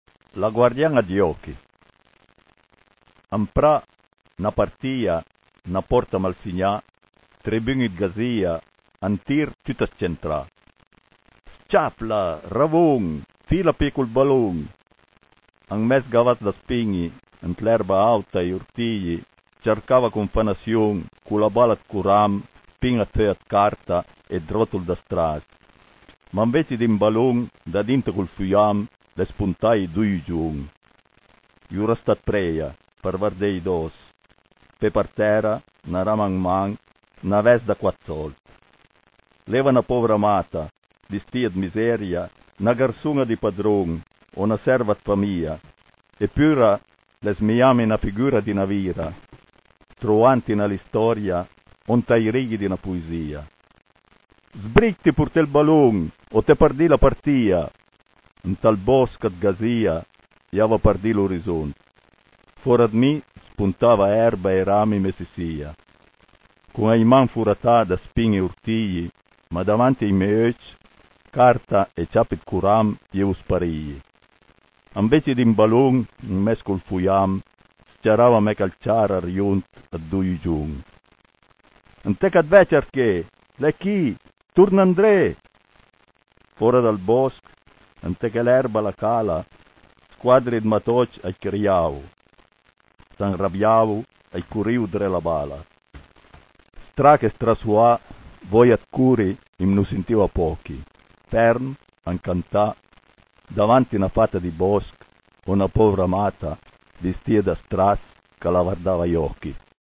cliché chi par sénti la puizìa recità da l'autùr